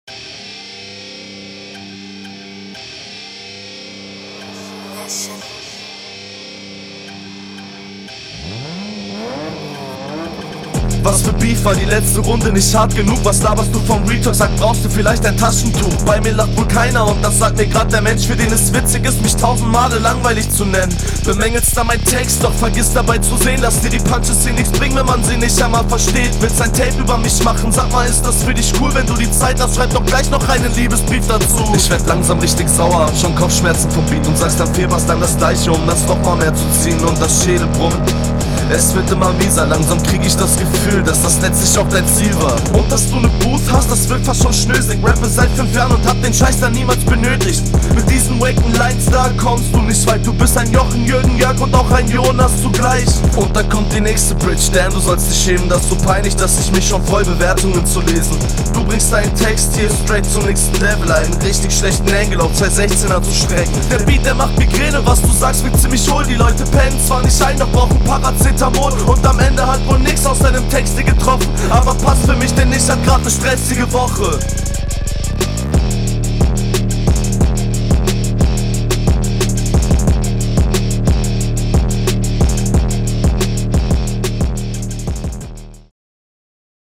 Flow: Sehr cool geflowt und wie ich finde angenehmer als der Gegner.